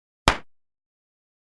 Bofetada (Una)
Sonidos: Especiales
Sonidos: Acciones humanas